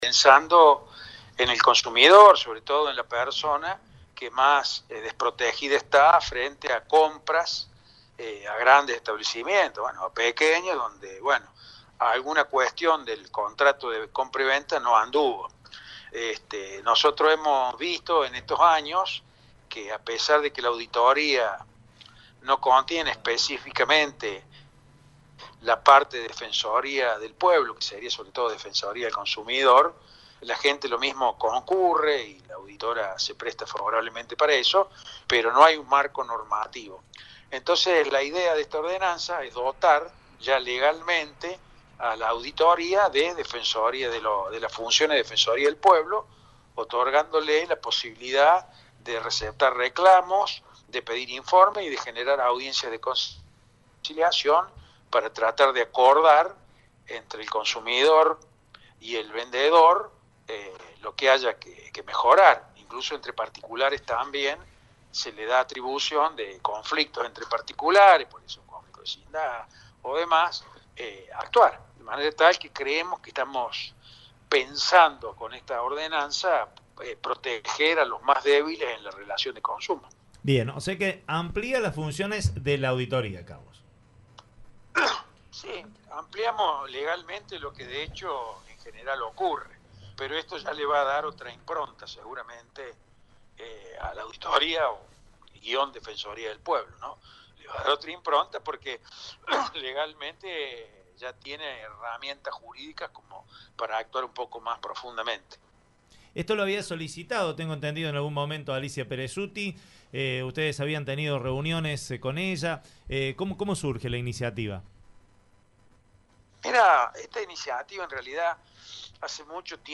El presidente del Concejo Deliberante y autor de la iniciativa, el abogado Carlos De Falco, explicó al respecto.